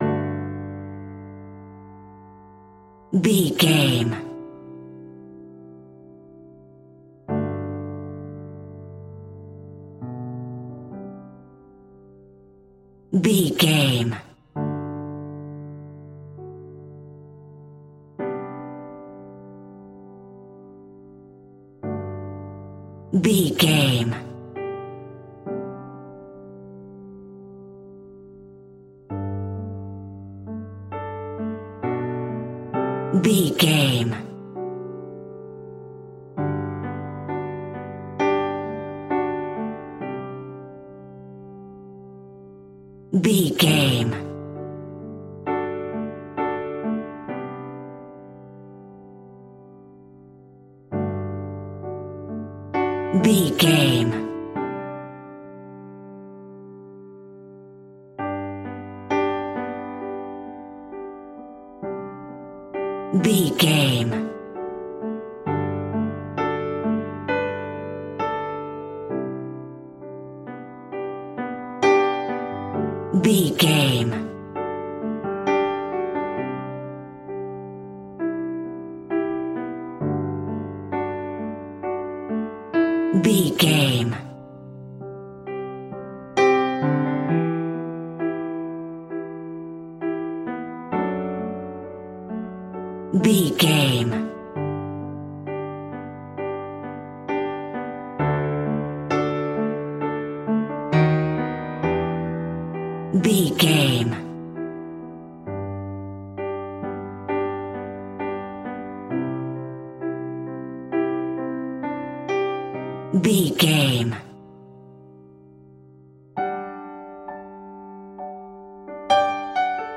Ionian/Major
Slow
tranquil